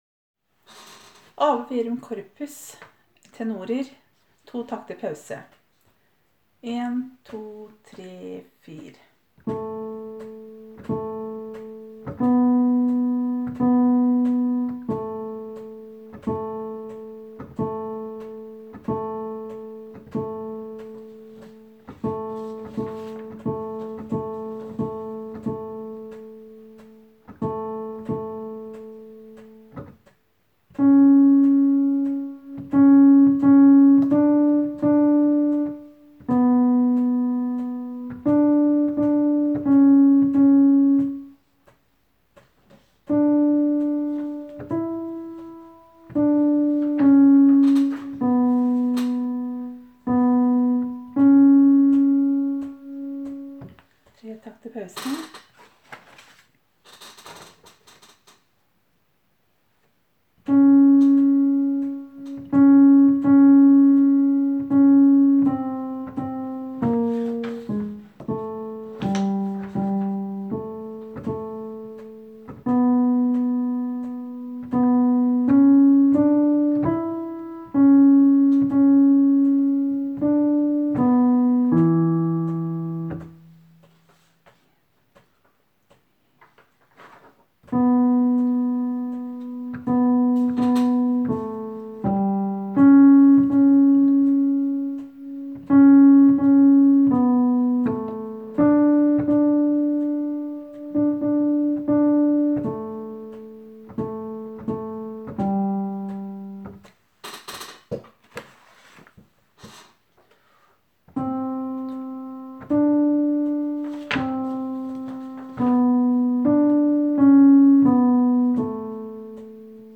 Jul 2017 Tenor (begge konserter)